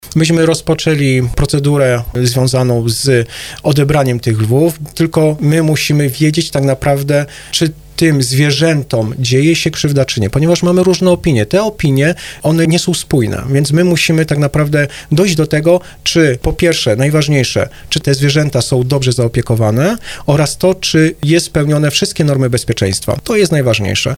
Krzysztof Szot na antenie Radia RDN Małopolska przyznał, że procedura ruszyła, ale nie jest to łatwe zadanie.